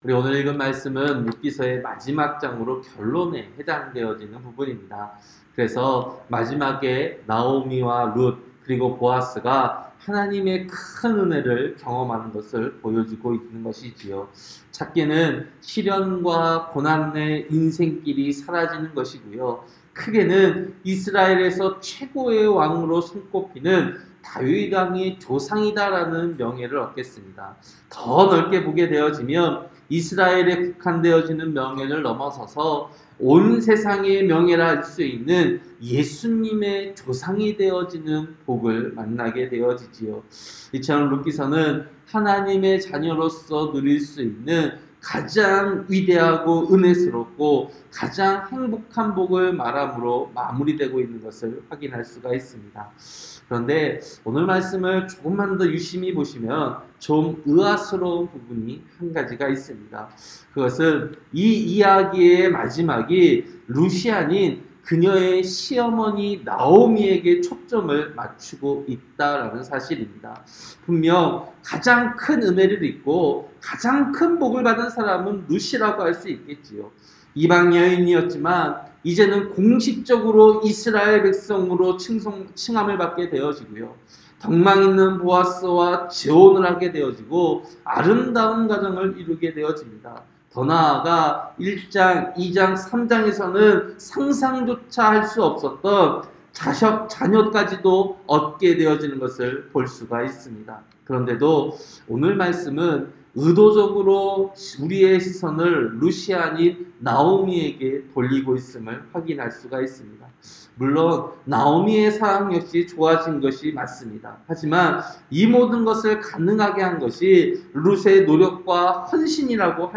새벽설교-룻기 4장